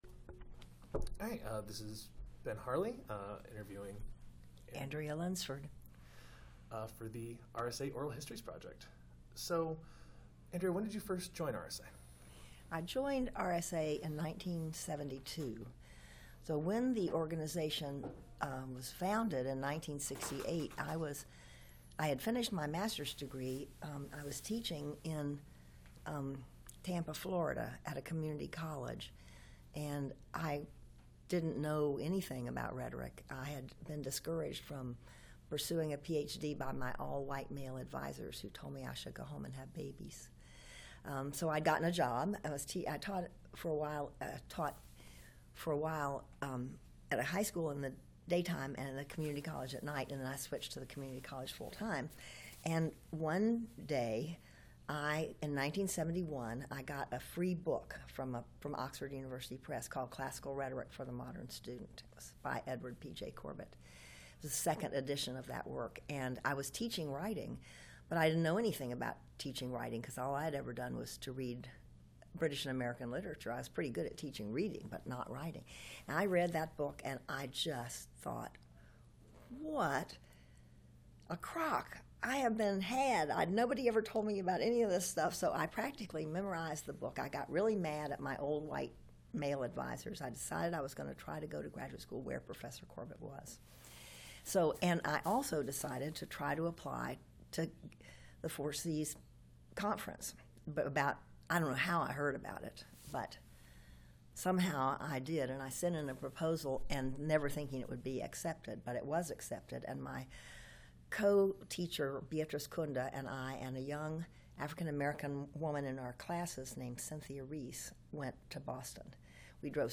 Andrea Lunsford Interview
Oral History
Location 2018 RSA Conference in Minneapolis, Minnesota